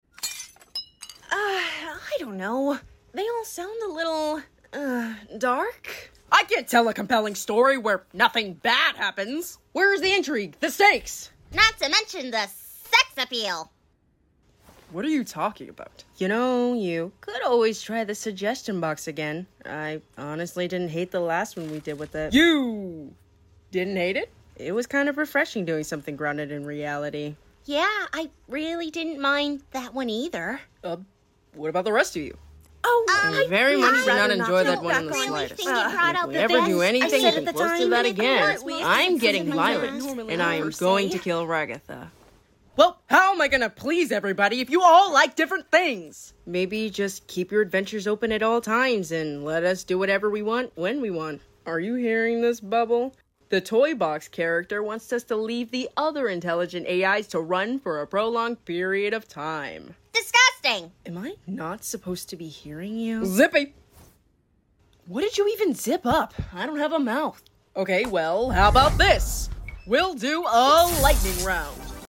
⚡I’ve been saying ‘disgusting’ in bubbles voice for DAYS.